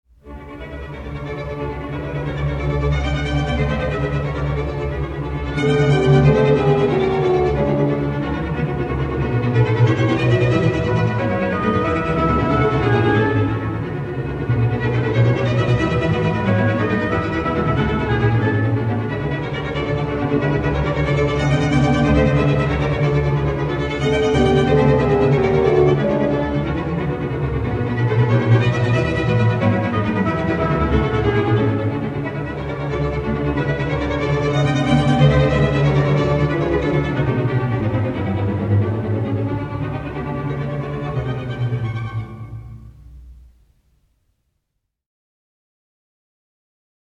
оркестровая версия